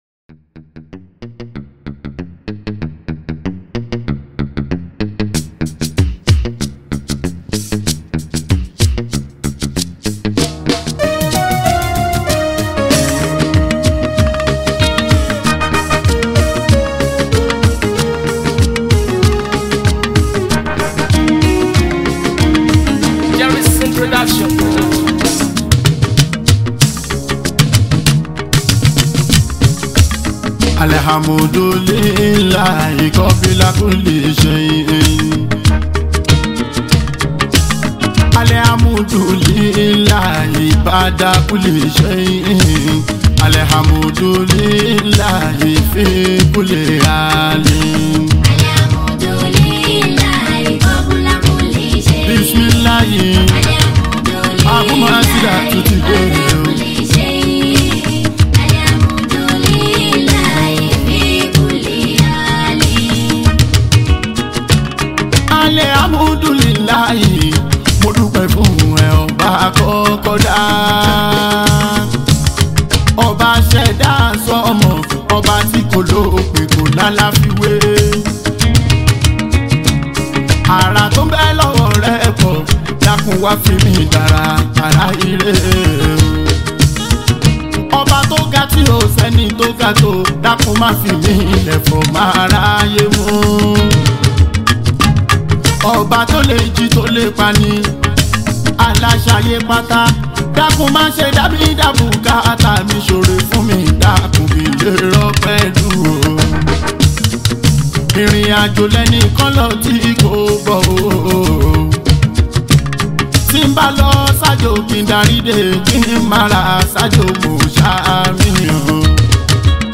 Yoruba Islamic Music 0
Yoruba Fuji Hit song
Nigerian Yoruba Islamic Song
Fuji Music